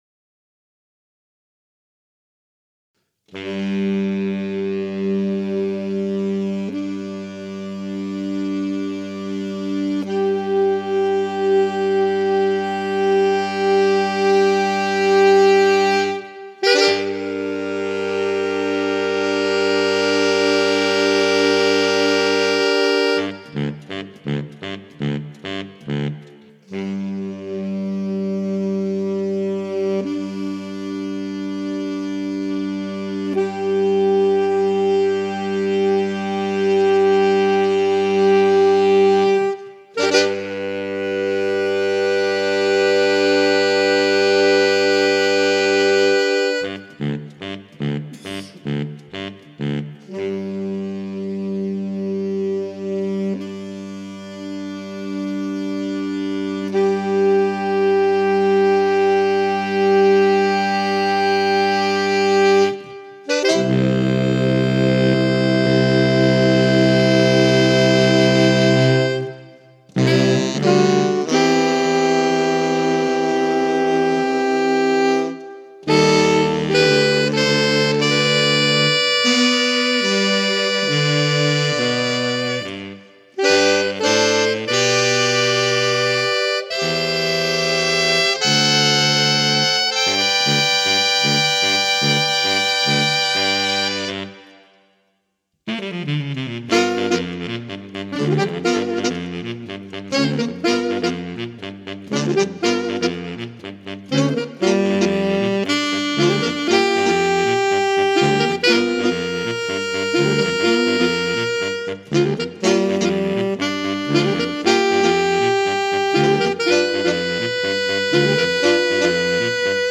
with all of the bold and brassy bravura
More Saxophone Quartet Music